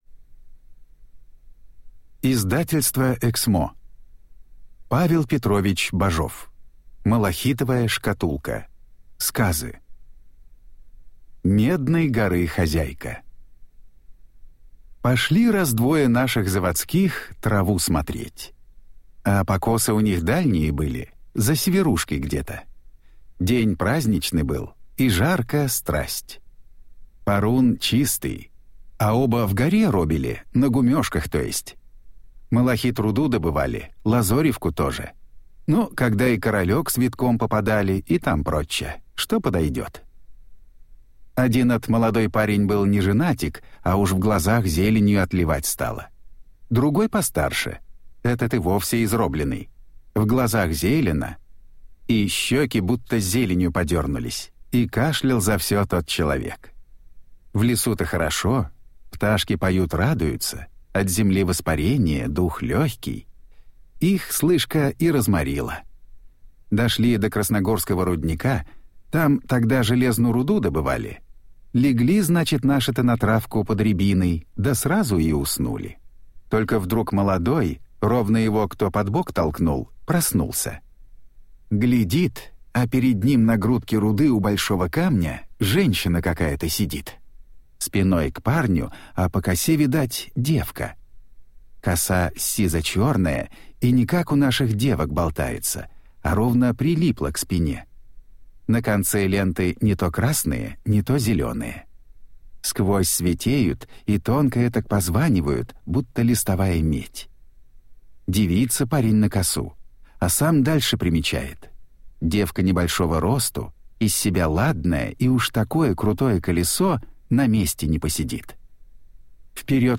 Аудиокнига Малахитовая шкатулка. Сказы